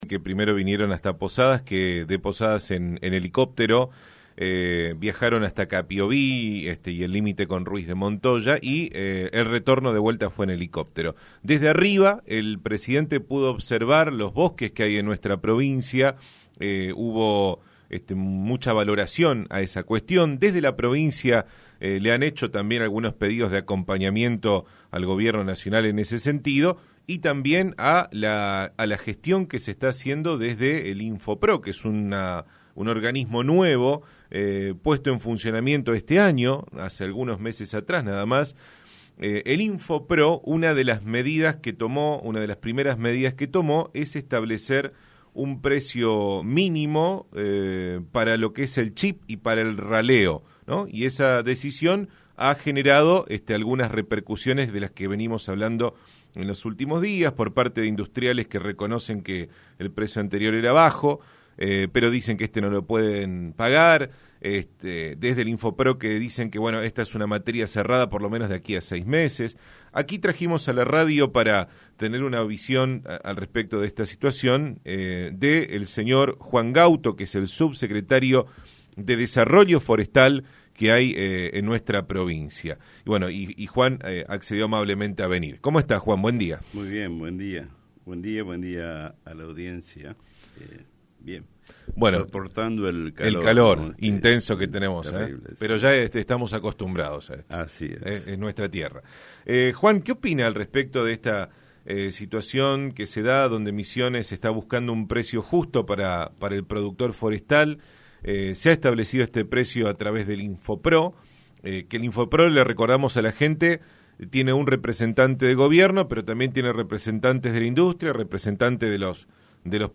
Video-Juan-Angel-Gauto-subsecretario-de-Desarrollo-Forestal-RADIO-LIBERTAD.mp3